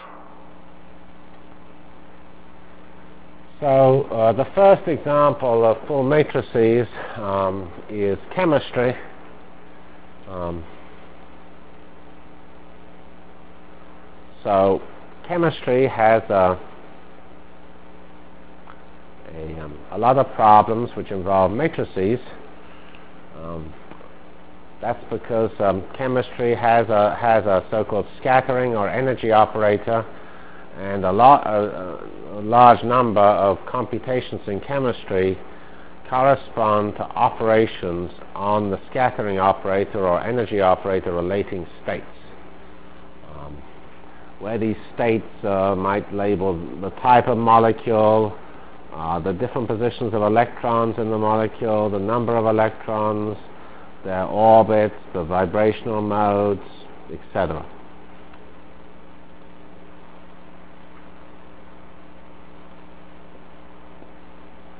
From CPS615-Linear Programming and Whirlwind Full Matrix Discussion Delivered Lectures of CPS615 Basic Simulation Track for Computational Science -- 5 Decemr 96.